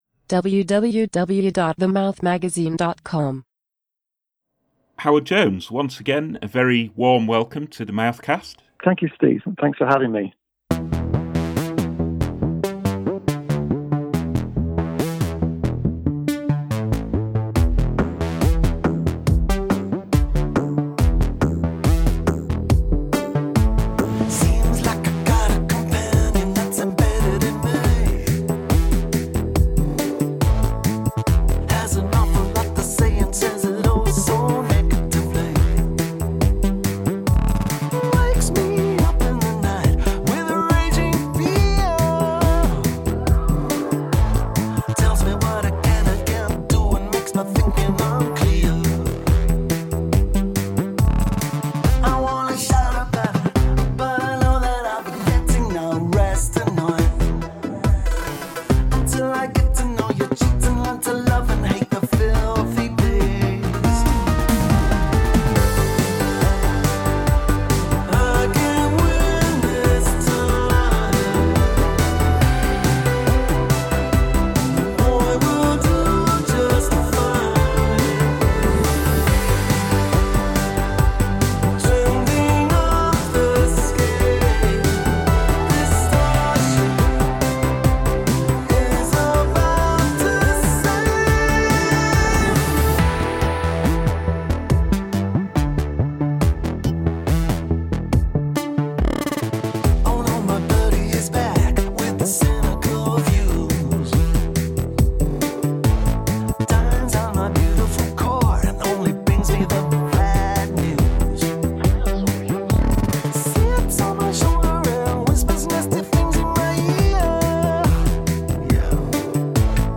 In this new podcast with The Mouth Magazine, recorded just ahead of the album and its subsequent tour, Jones discusses TRANSFORM…